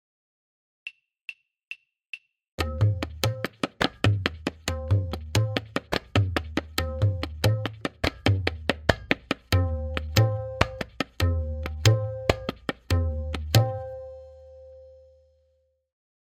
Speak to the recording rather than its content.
M8.5-Sadharan-Palla-Click.mp3